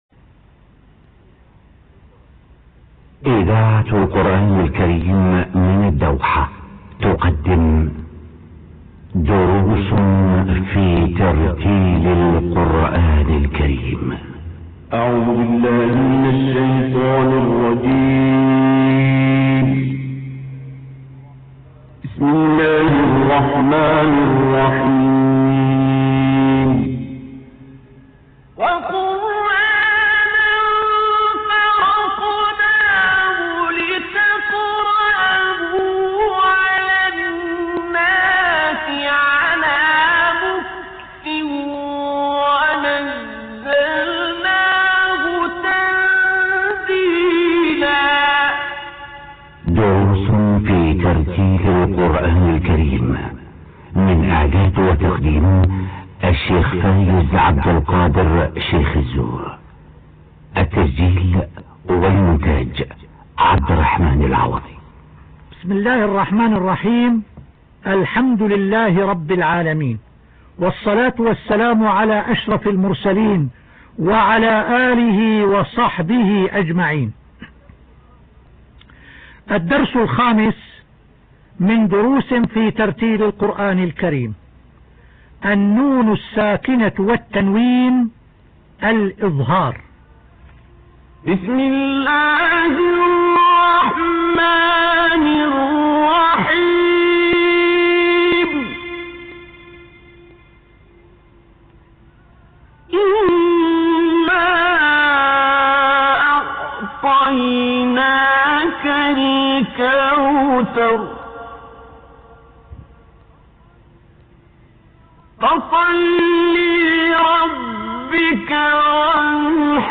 الدروس المسموعة